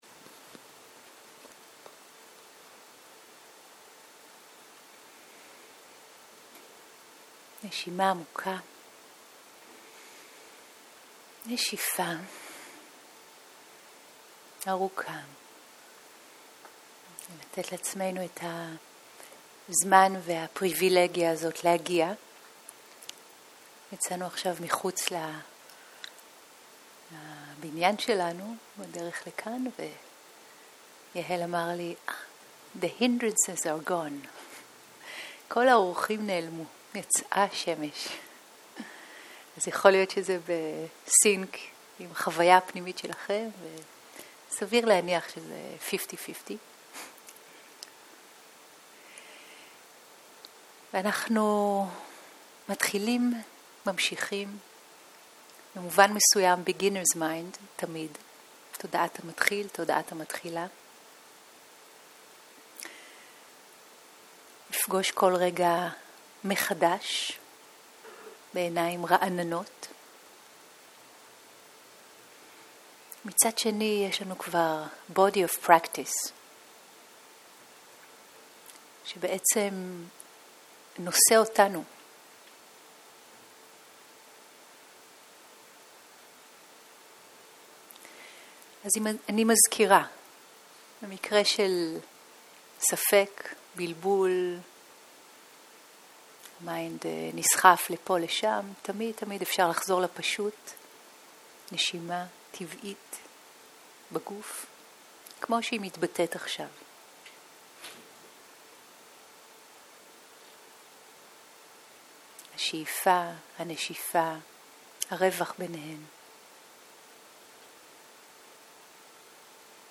מדיטציה מונחית - לנוח בתוך המנדלה